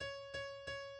key Bm
transposed -5 from original Em